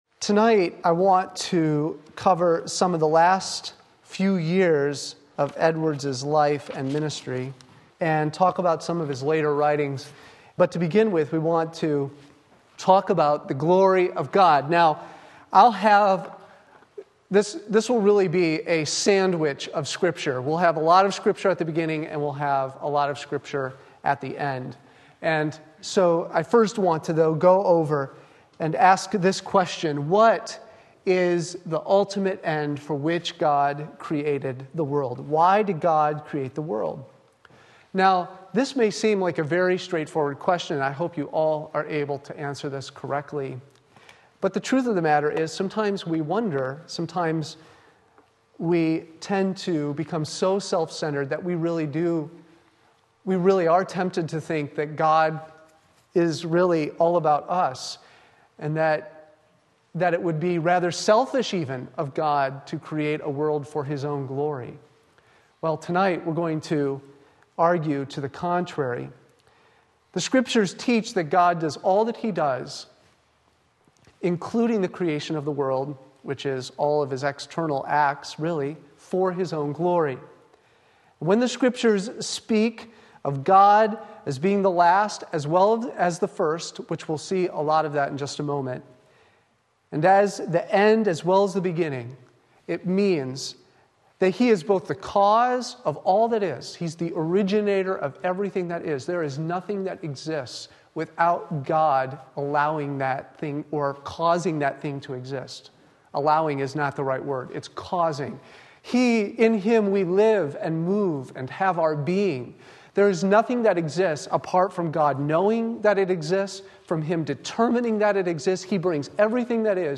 Sermon Link
and the Glory of God Isaiah 48:11-12 Wednesday Evening Service